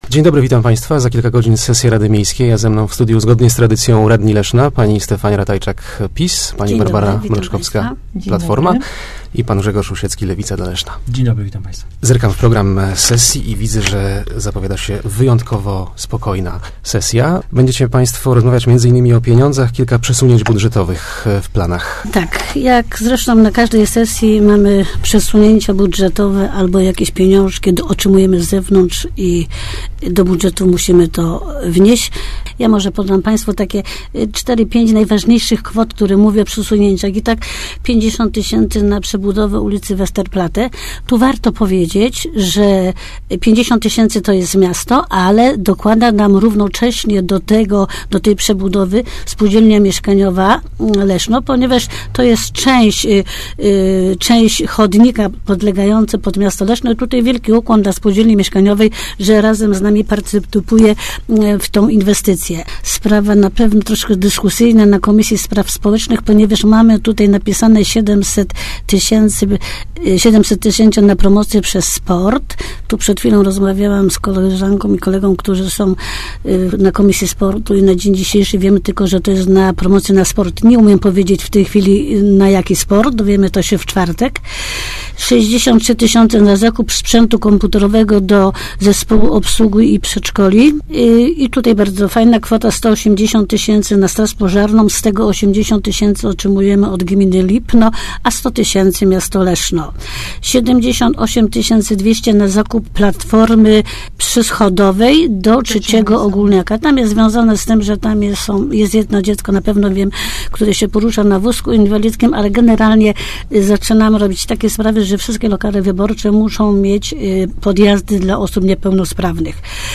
Nie mo�emy d�u�ej odwleka� adaptacji internatu Zespo�u Szkó� Rolniczo – Budowalnych na potrzeby Zespo�u Szkó� Specjalnych – mówili w Rozmowach Elki radni Stefania Ratajczak z PIS, Barbara Mroczkowska z PO oraz Grzegorz Rusiecki z Lewicy dla Leszna. Na dzisiejszej sesji rada b�dzie podejmowa� decyzj� o zdj�ciu z bud�etu �rodków zabezpieczonych na ten cel.